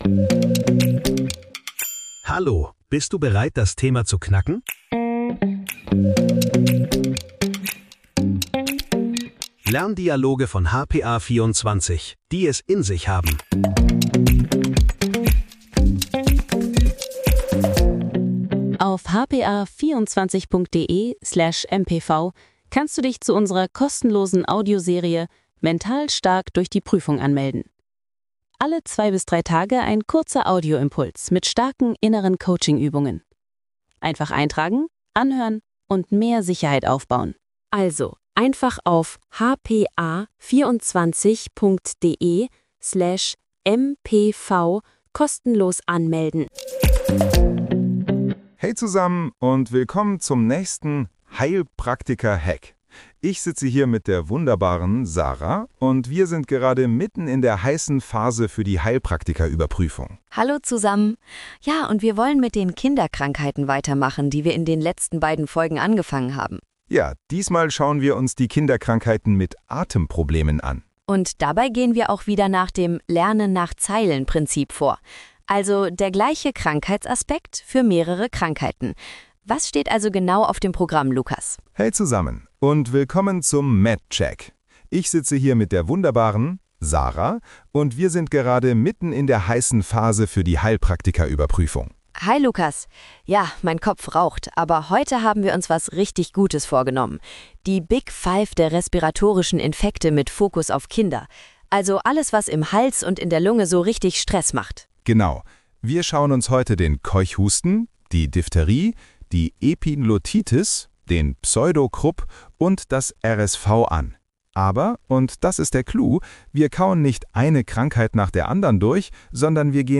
Lerndialoge für deinen Prüfungserfolg